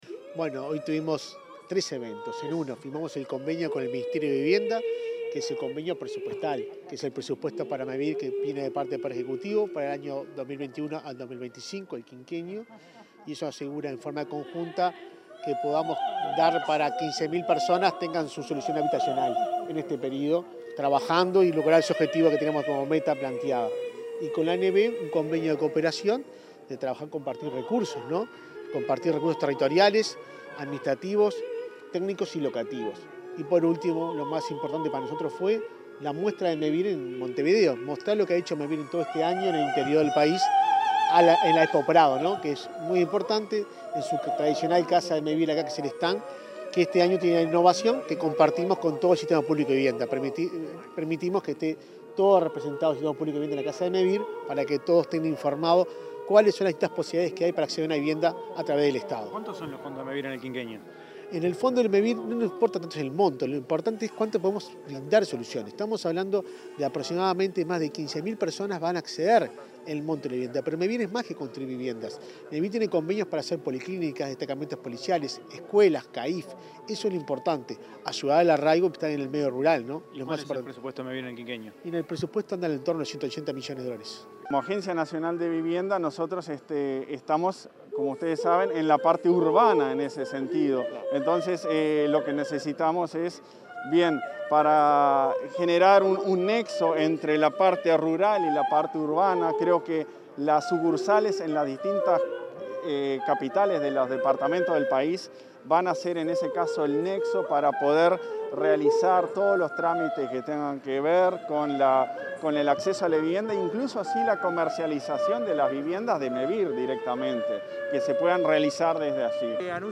Palabras de los presidentes de Mevir y de la Agencia Nacional de Vivienda 13/09/2021 Compartir Facebook X Copiar enlace WhatsApp LinkedIn El presidente de Mevir, Juan Pablo Delgado, y el de la Agencia Nacional de Vivienda, Klaus Mill, dialogaron con la prensa sobre los convenios firmaron entre ambos organismos, este lunes 13 en la Expo Prado.